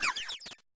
Cri de Lilliterelle dans Pokémon Écarlate et Violet.